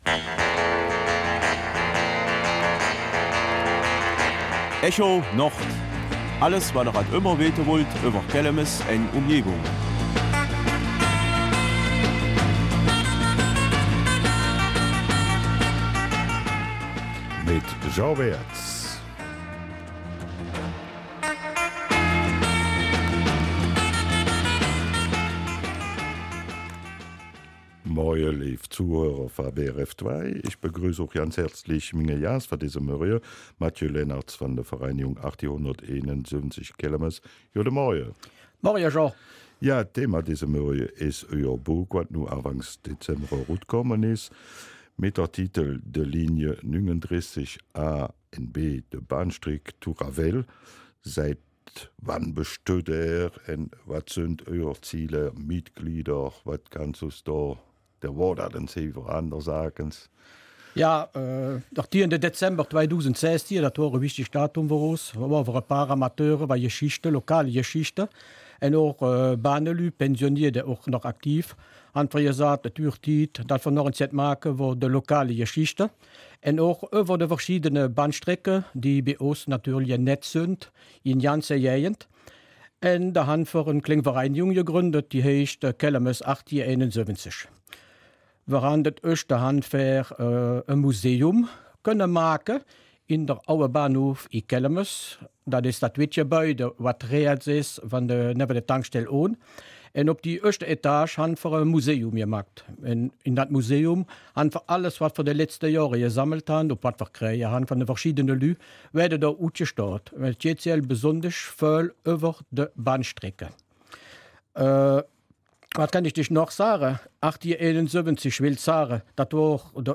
Kelmiser Mundart: Linie 39, von der Bahnstrecke zum Ravel